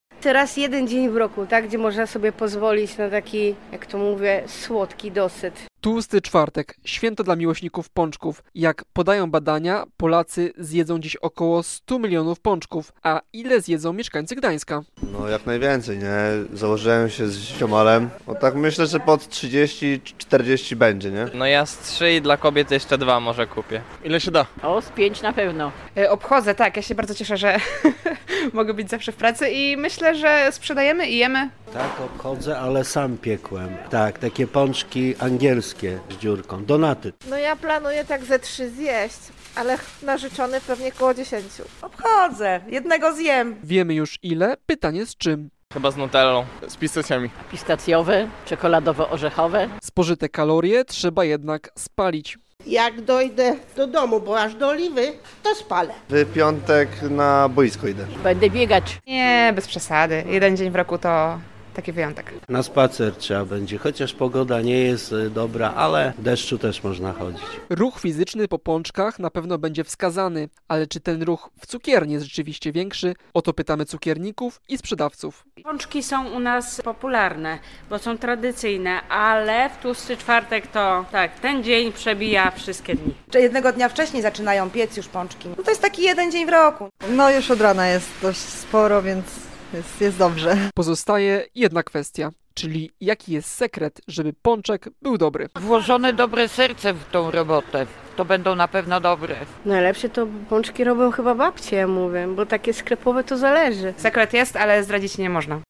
Odwiedzamy dziś gdańskie cukiernie i pytamy mieszkańców ile paczków zjedzą.